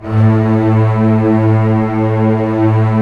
Index of /90_sSampleCDs/Roland - String Master Series/STR_Cbs Arco/STR_Cbs2 Orchest